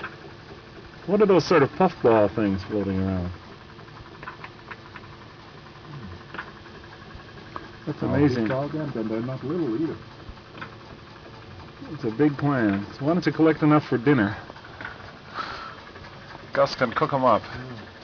From inside Alvin